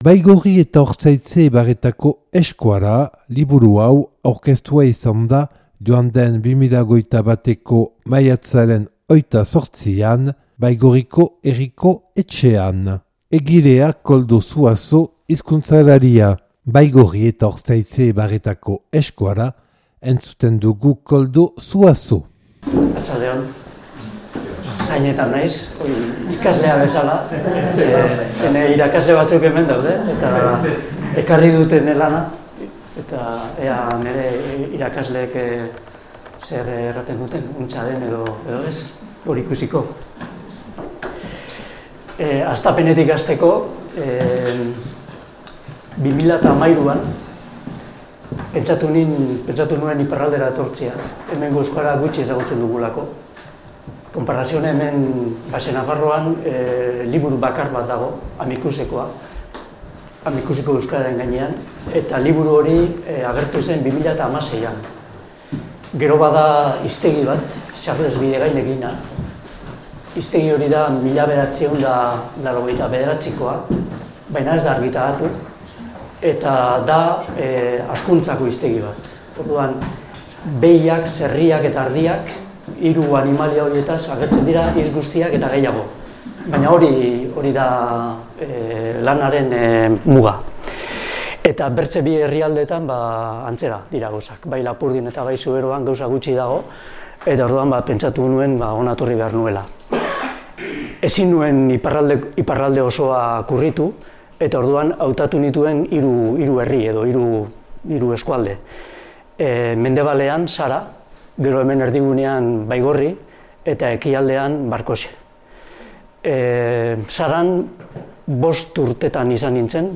Baigorri eta Ortzaize ibarretako euskara liburuaren aurkezpena
(Baigorriko Herriko Etxean grabaua 2021. maiatzaren 28an).